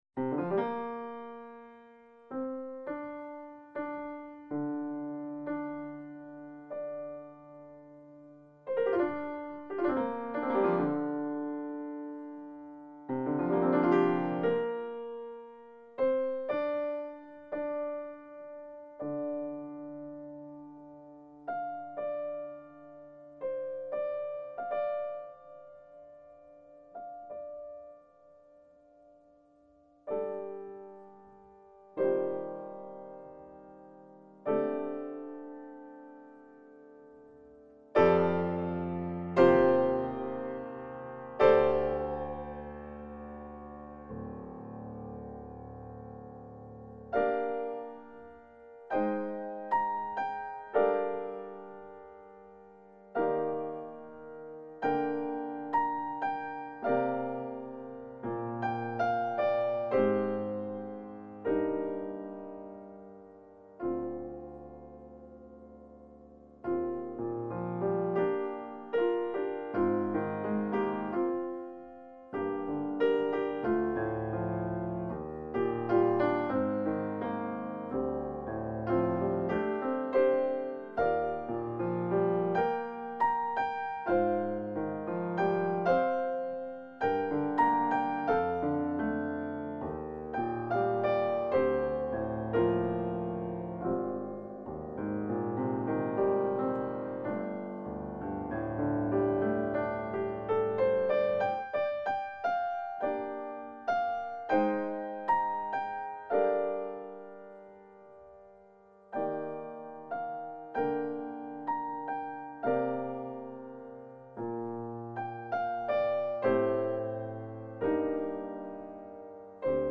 器樂演奏家系列
鋼琴演奏
滑过黑键与键，尝试以钢琴来诠释古琴曲，